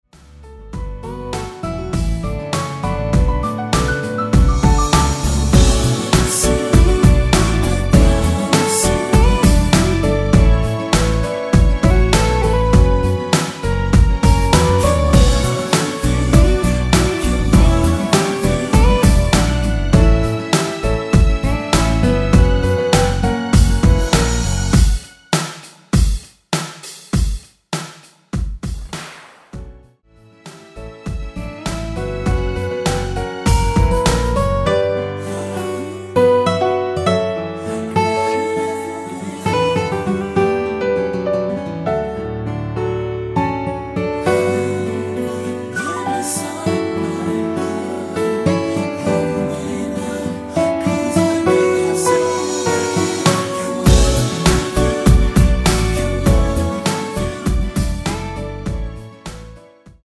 (-2) 내린 코러스 포함된 MR 입니다.(미리듣기 참조)
Bm
앞부분30초, 뒷부분30초씩 편집해서 올려 드리고 있습니다.